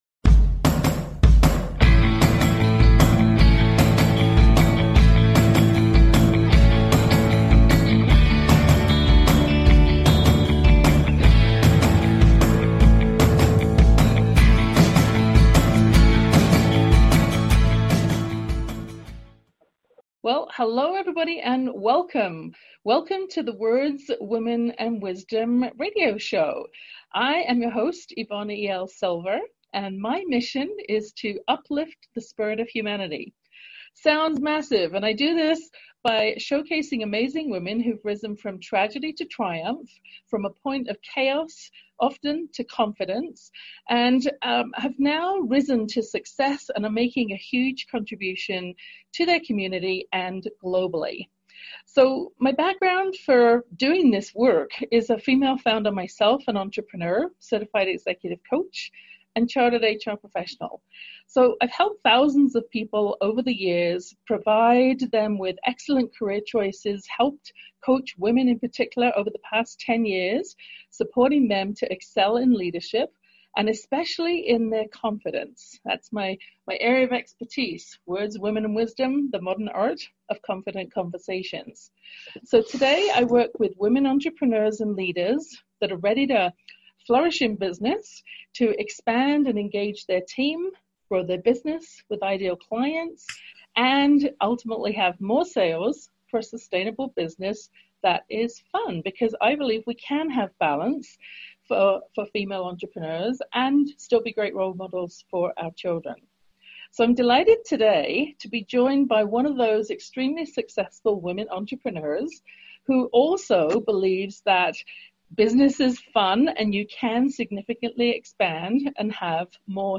Talk Show Episode, Audio Podcast, Words Women and Wisdom Show and Guest